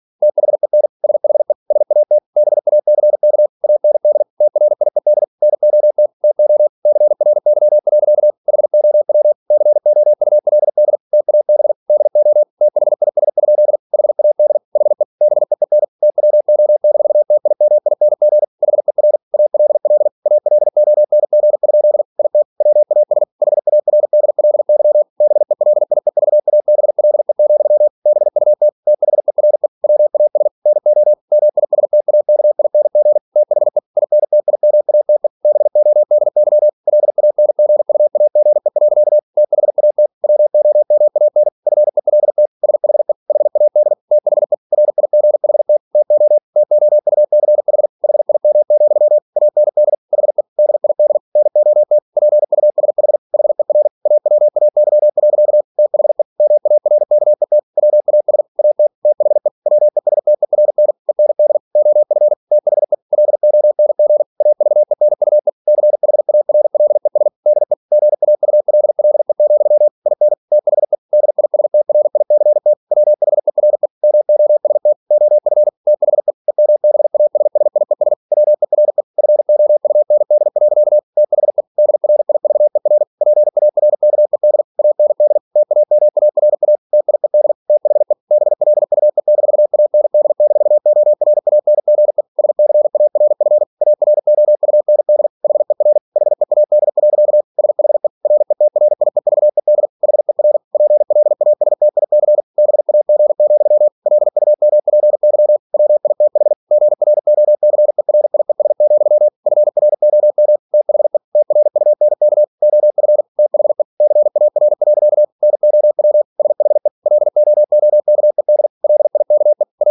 Never 47wpm | CW med Gnister